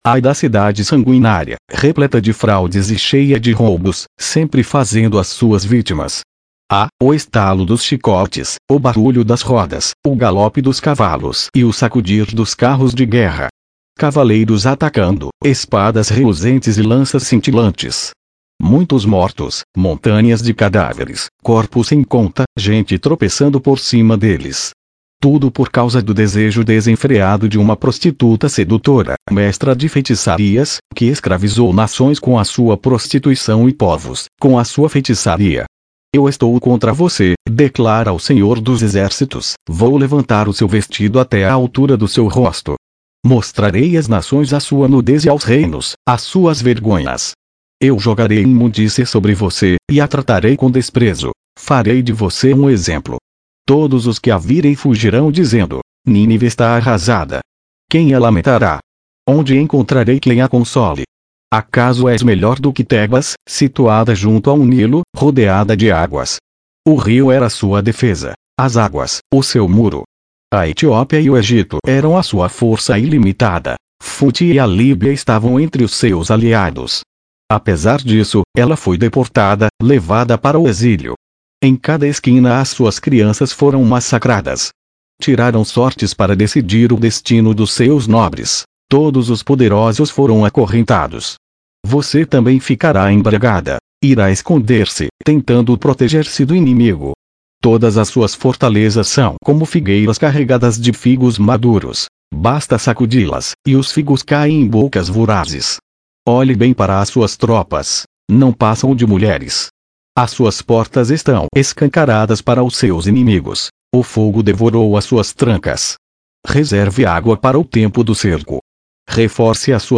Leitura na versão Nova Versão Internacional - Português